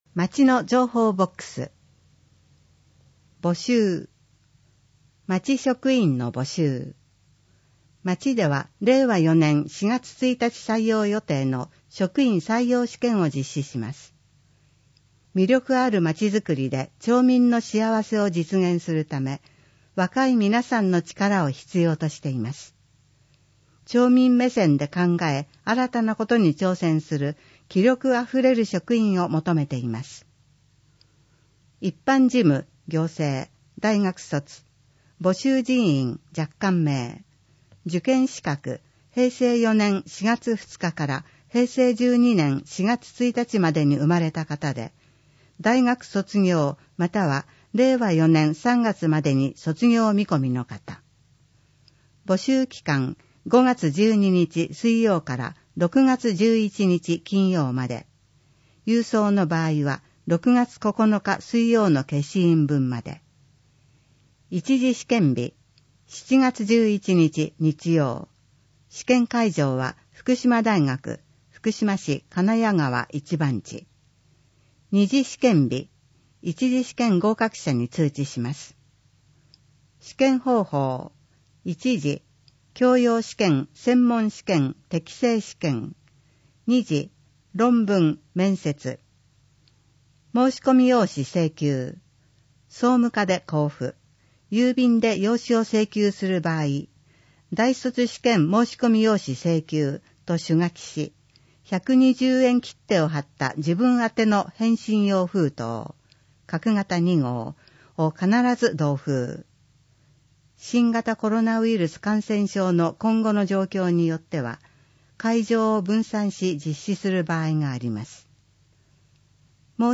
＜外部リンク＞ 声の広報 広報紙の内容を音声で提供しています。